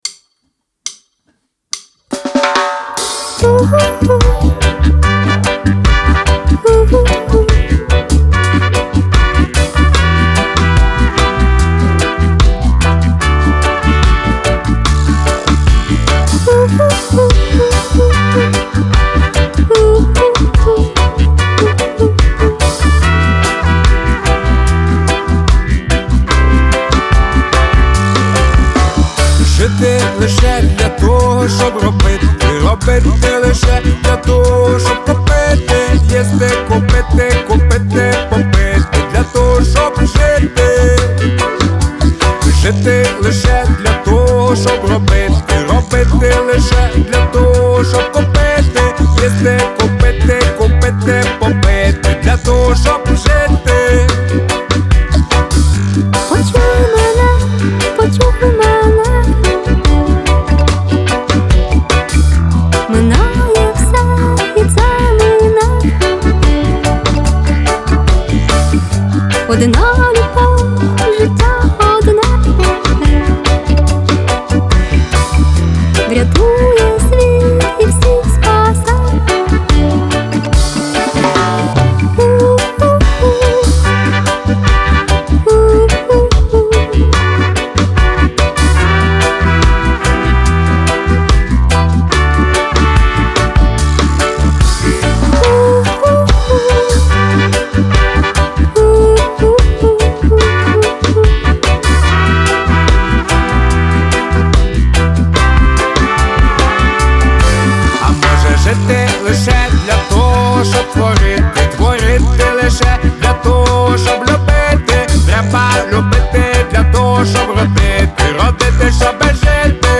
• Жанр: Reggae